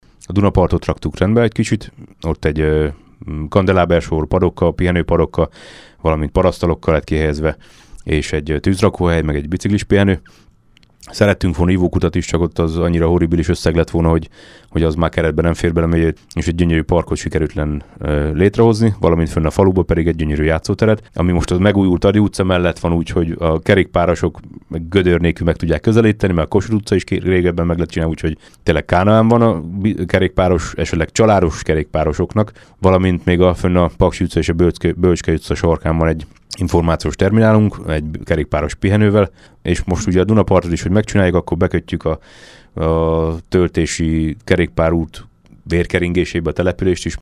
Folyamatosan fejlődik Madocsa, melyhez kiváló lehetőségeket nyújt többek között a Jövőnk Energiája Térségfejlesztési Alapítvány és a Magyar Falu Program pályázatai – mondta el rádiónknak Baksa Ferenc, a település polgármestere.